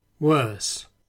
English s is loud, with a piercing, high timbre, which can cut through background noise such as chatter at a social gathering.
And here is worse, with the strong final s that is so characteristic of English: